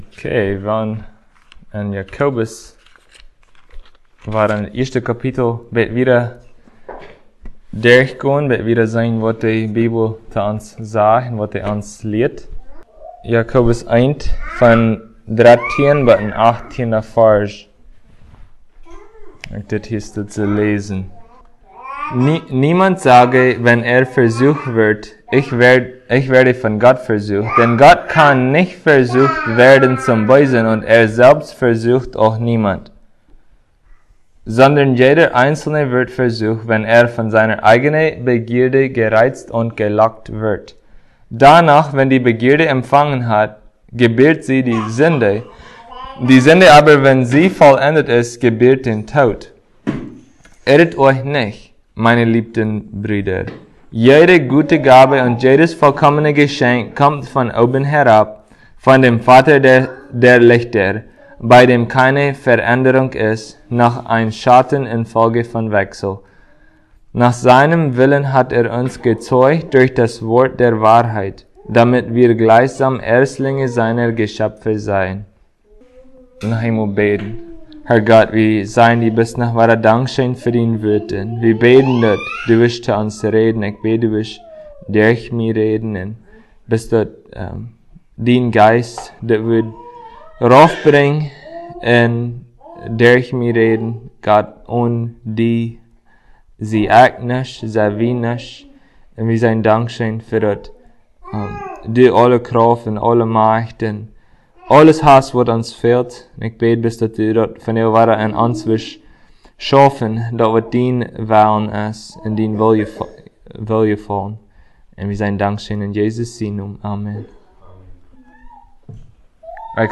Passage: James 1:13-18 Service Type: Sunday Plautdietsch « Christian Conduct